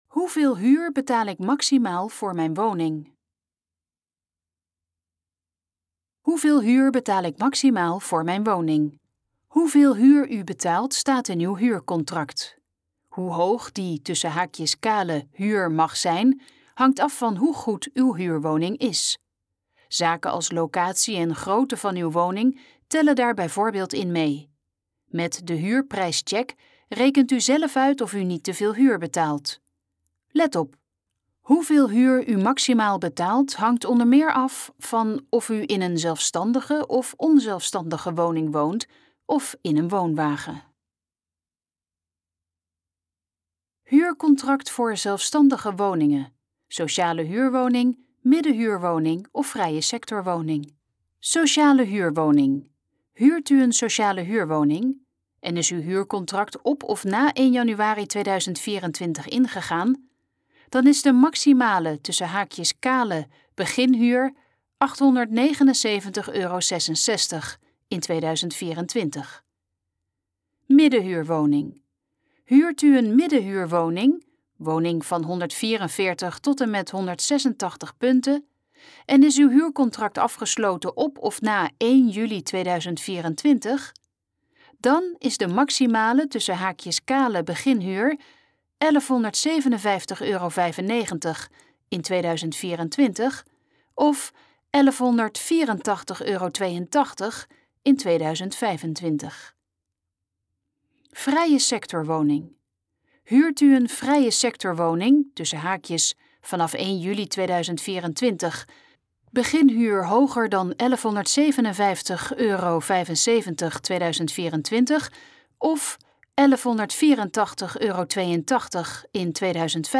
Dit is de gesproken versie van de pagina Hoeveel huur betaal ik maximaal voor mijn woning?